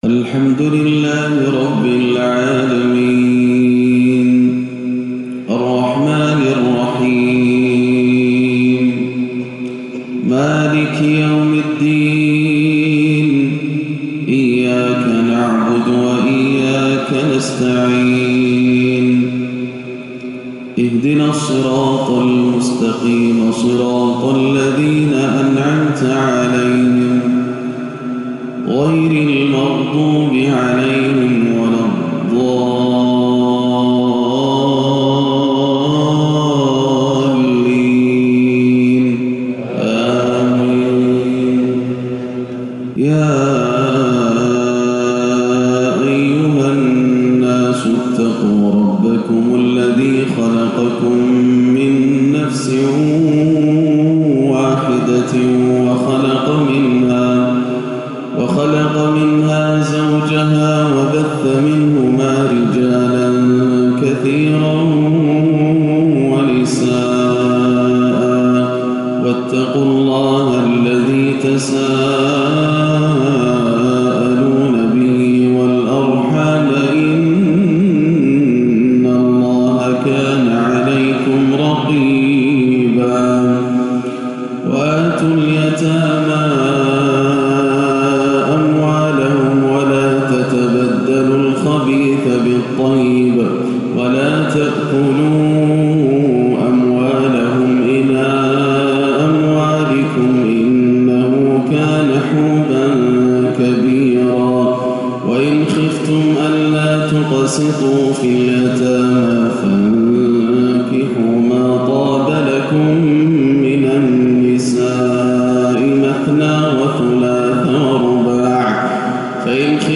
فجر الثلاثاء 8-4-1439هـ فواتح سورة النساء 1-10 > عام 1439 > الفروض - تلاوات ياسر الدوسري